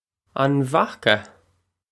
This comes straight from our Bitesize Irish online course of Bitesize lessons.